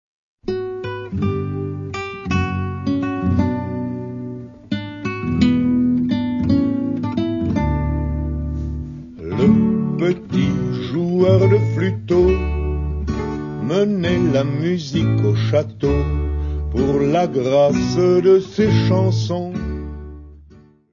: stereo; 12 cm + folheto
Music Category/Genre:  World and Traditional Music